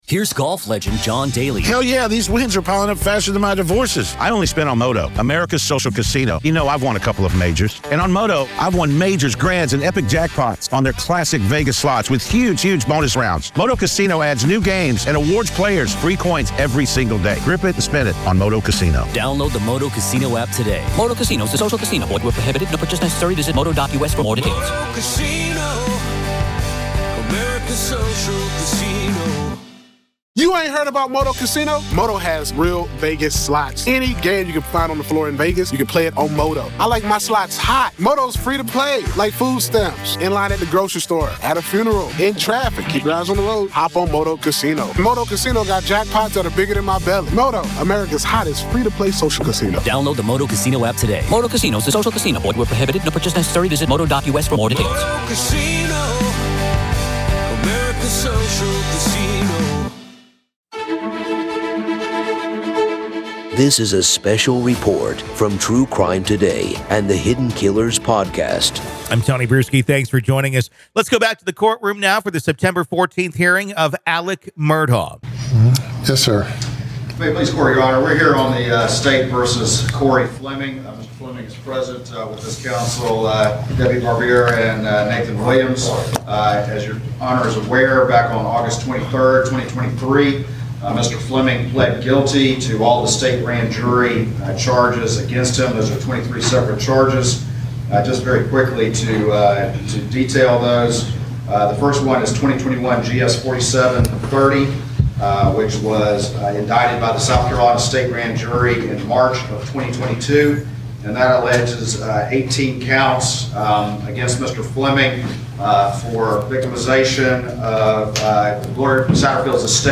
Join us as we delve into raw audio excerpts from the September 14th hearing of Alex Murdaugh.